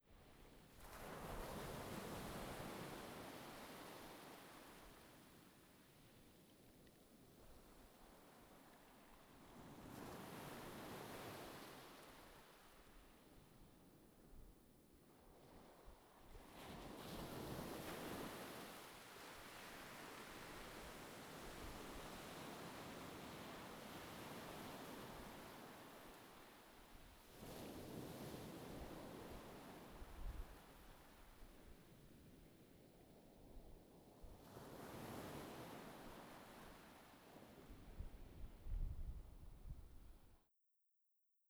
beach-tide.wav